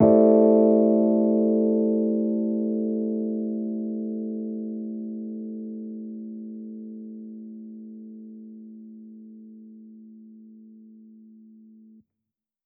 Index of /musicradar/jazz-keys-samples/Chord Hits/Electric Piano 2
JK_ElPiano2_Chord-Am11.wav